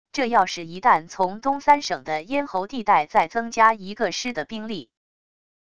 这要是一旦从东三省的咽喉地带在增加一个师的兵力wav音频生成系统WAV Audio Player